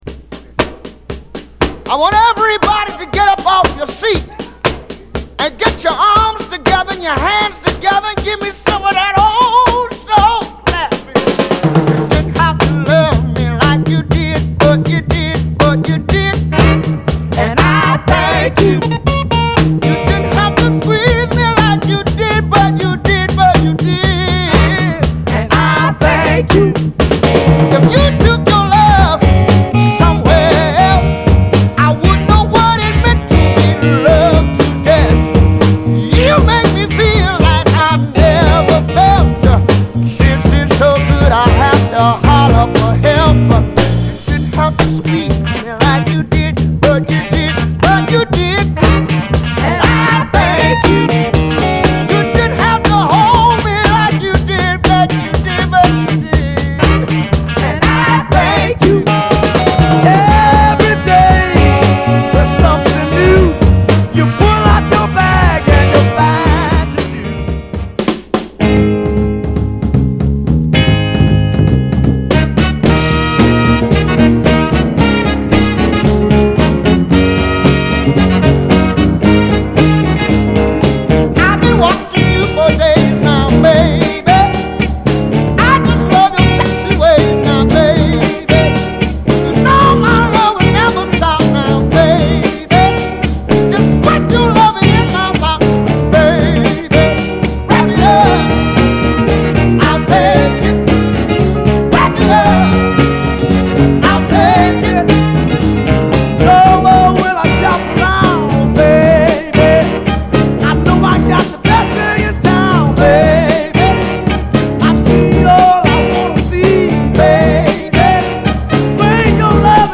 ファンキーなホーン隊がブレイクビーツ系でもサンプリングされていた
盤はいくつか薄い表面スレ箇所ありますが、グロスが残っておりプレイ良好です。
※試聴音源は実際にお送りする商品から録音したものです※